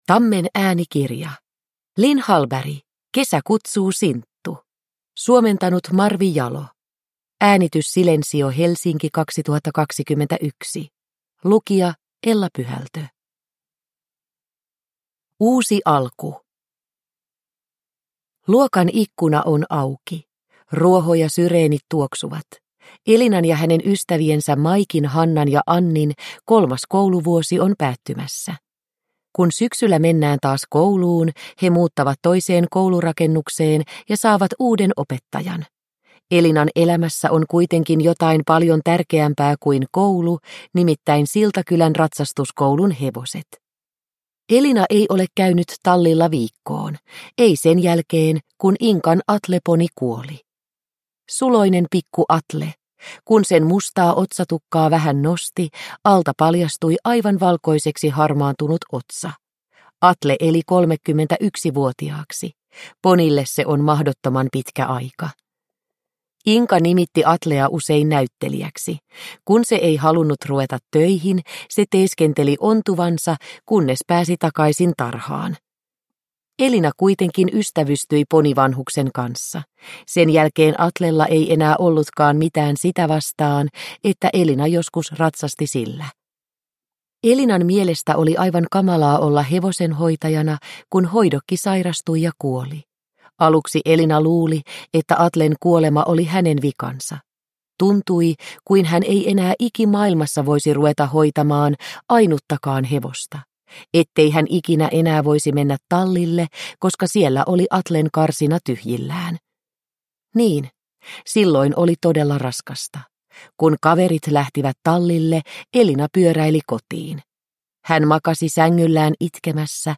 Kesä kutsuu, Sinttu! – Ljudbok – Laddas ner